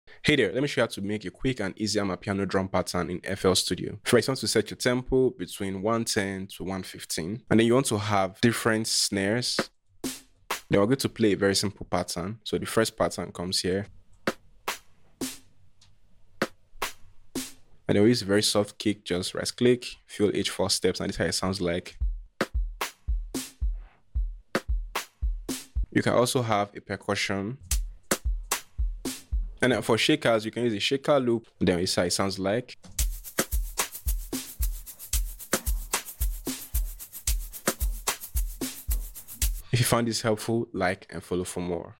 Amapiano fl studio drum tutorial sound effects free download